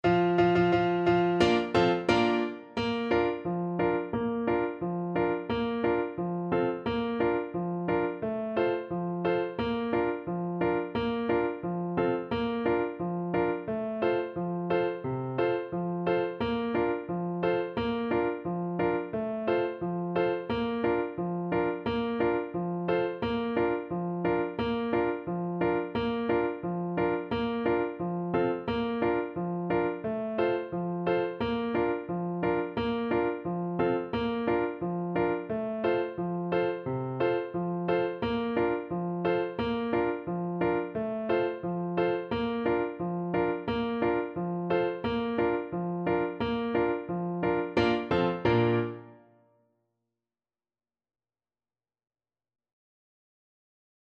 2/4 (View more 2/4 Music)
Steady march =c.88